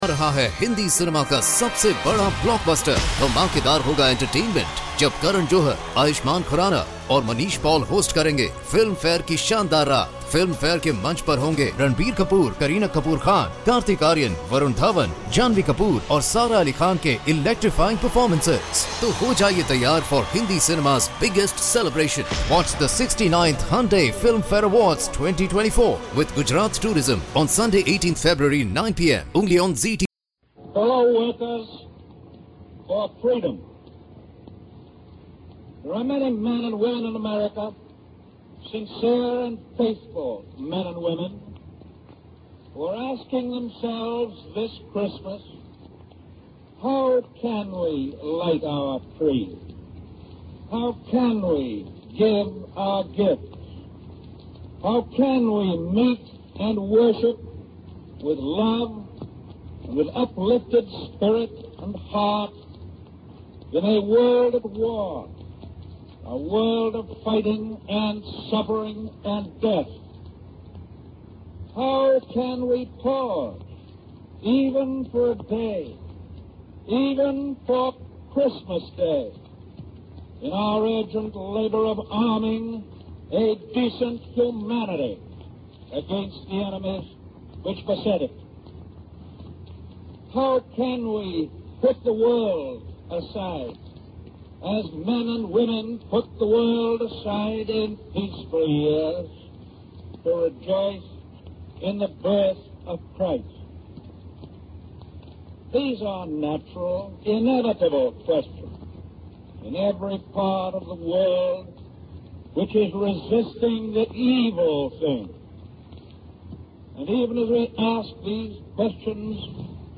OTR Christmas Shows - Christmas Broadcast - 1941-12-24 FDR